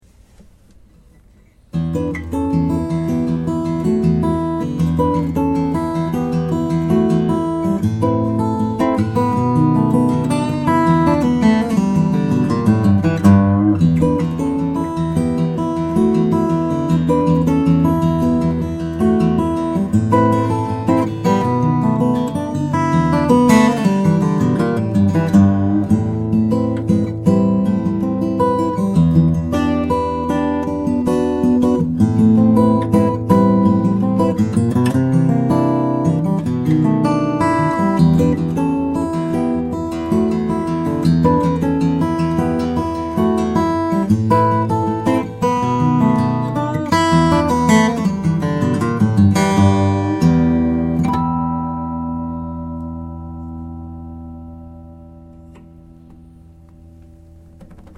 His voice is so sweet, clear and rich.